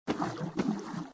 AV_foostep_walkloop_water.ogg